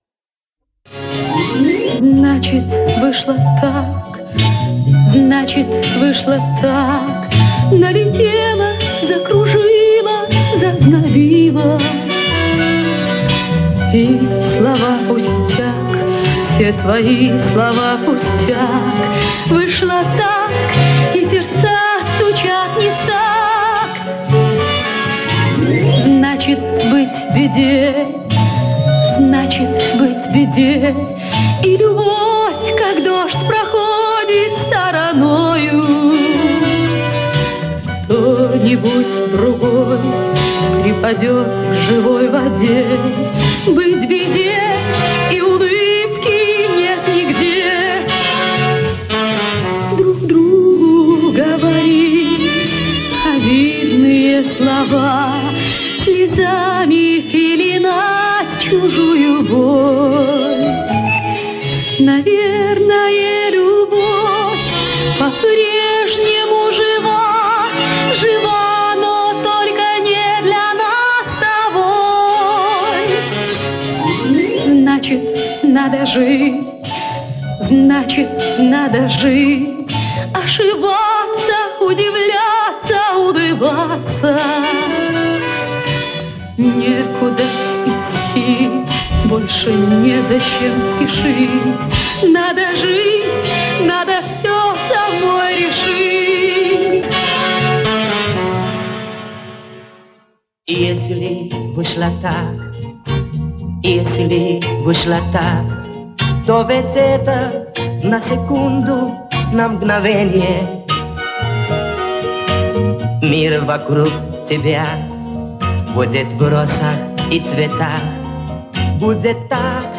Сделал ремастеринг этой песни.